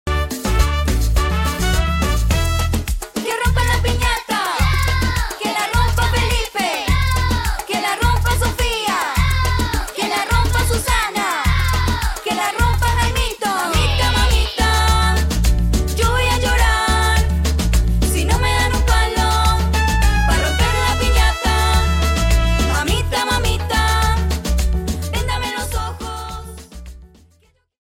Canción para cumpleaños